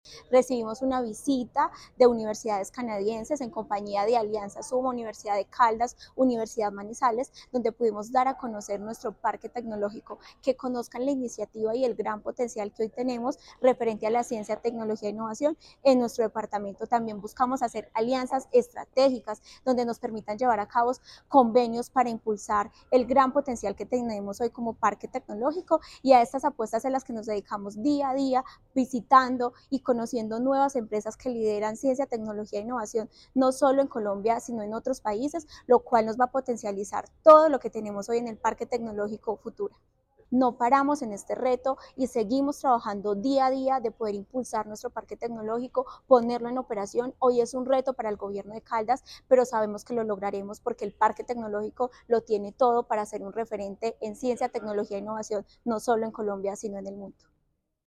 Tania Echeverry Rivera, secretaria de Desarrollo, Empleo e Innovación de Caldas.
Tania-Echeverry-Rivera-Secretaria-de-Desarrollo-Empleo-e-Innovacion-de-Caldas-2.mp3